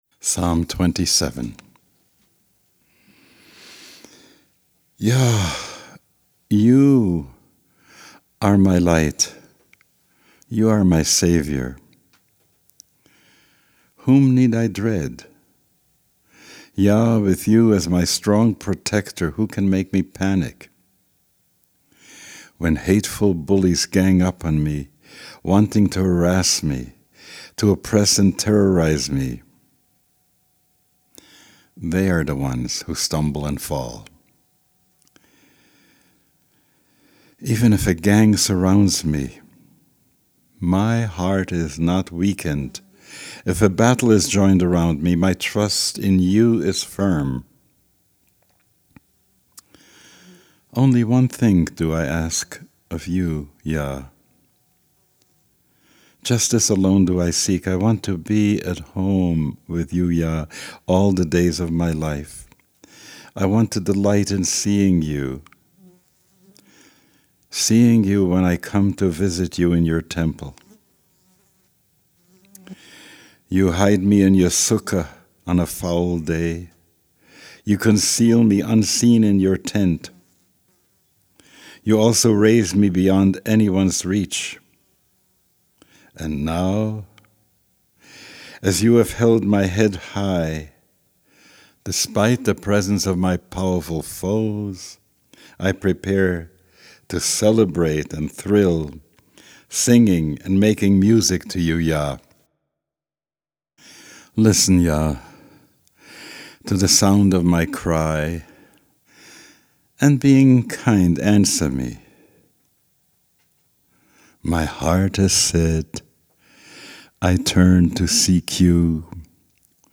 One tool for Elul discernment is the practice of praying Psalm 27 each day. Here is an English translation of Reb Zalman z”l (available in Psalms in a Translation for Praying ), and a recording of Reb Zalman offering this Psalm.